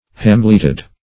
Hamleted \Ham"let*ed\, p. a. Confined to a hamlet.